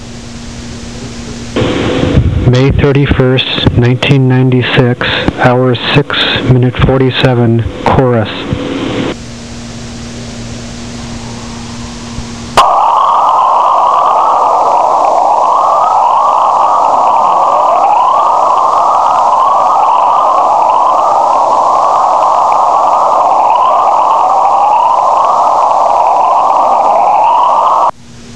Chorus Emissions ).
chorus.wav